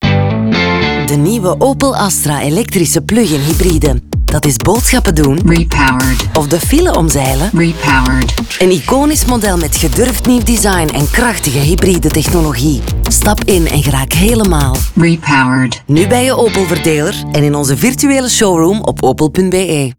Radio Production: Sonhouse